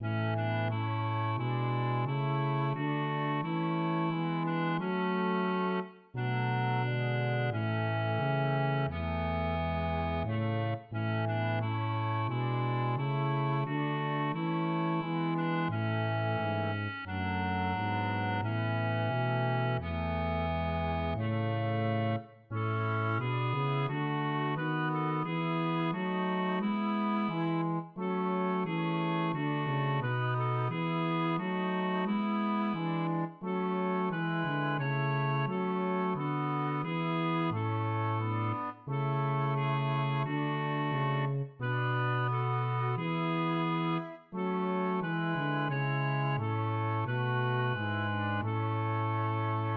(Salve Regina, römischer Choral, 11.